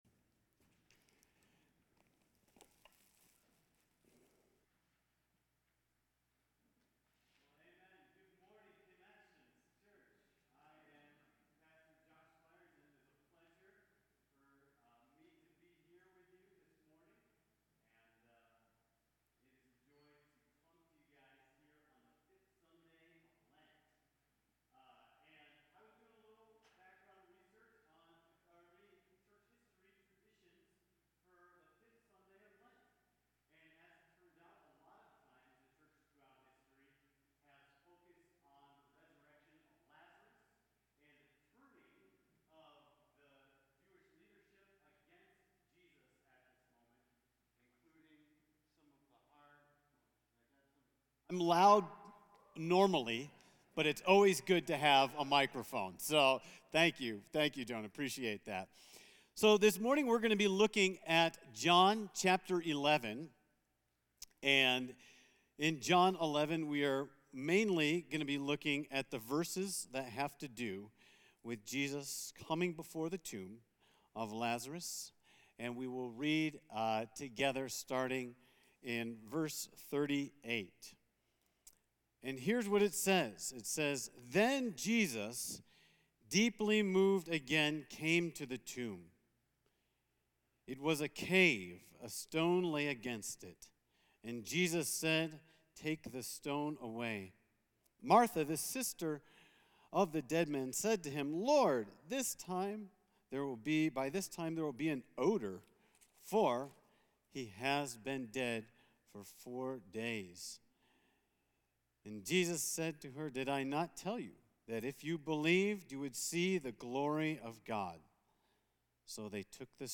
A message from the series "We are the Church."